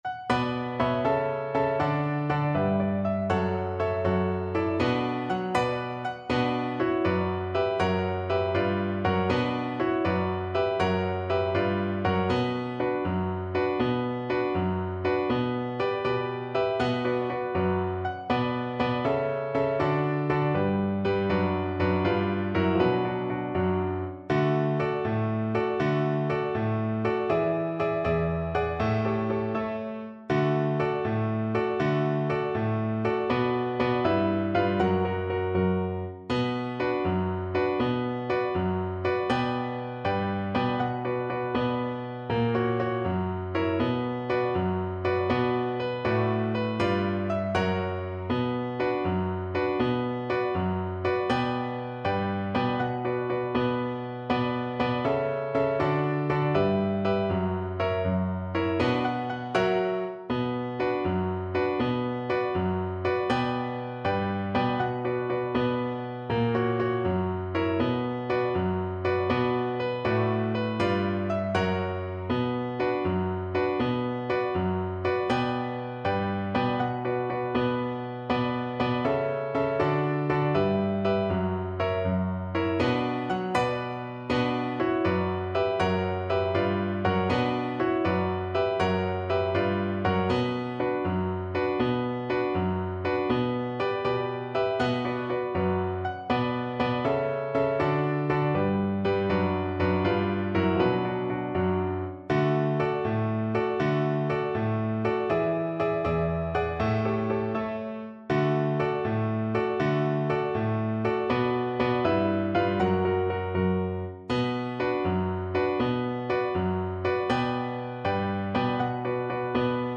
Viola
6/8 (View more 6/8 Music)
B4-B5
B minor (Sounding Pitch) (View more B minor Music for Viola )
Brightly, but not too fast
Classical (View more Classical Viola Music)